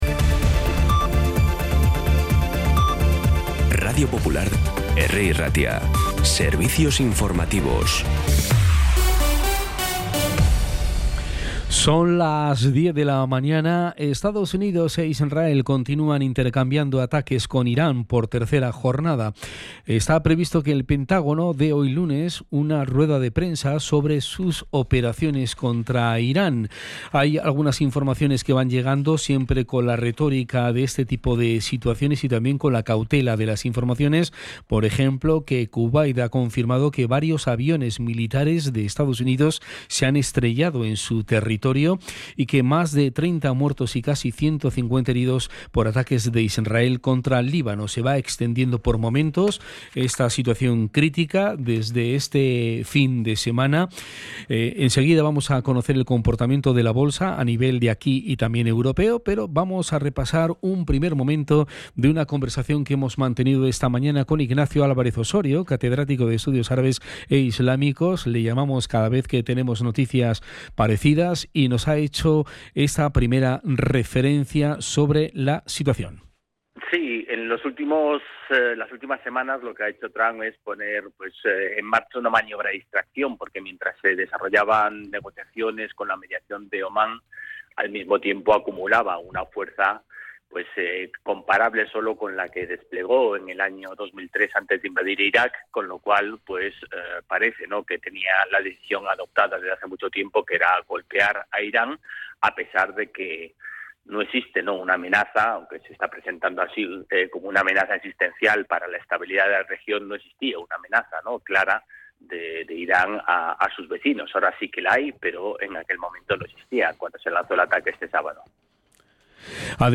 Las noticias de Bilbao y Bizkaia del 2 de marzo a las 10
Podcast Informativos
Los titulares actualizados con las voces del día. Bilbao, Bizkaia, comarcas, política, sociedad, cultura, sucesos, información de servicio público.